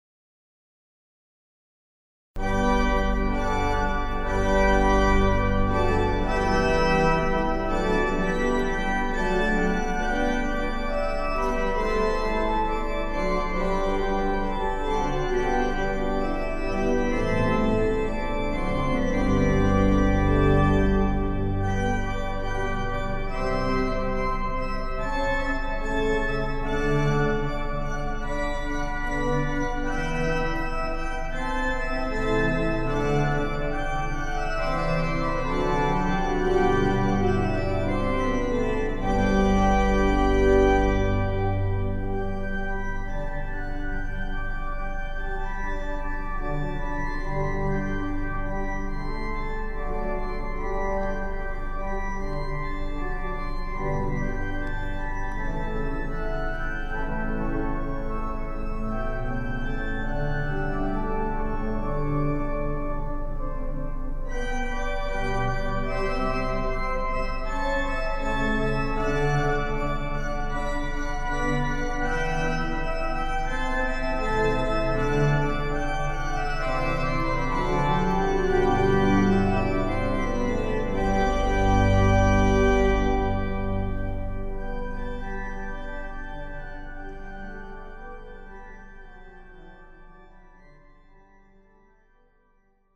Basilica del Sacro Cuore di Cristo Re
Concerto organistico in onore dei nuovi Santi Papa Giovanni XXIII e Papa Giovanni Paolo II
Concerto grosso n.2 in sol maggiore, BWV973 ( allegro, adagio, allegro)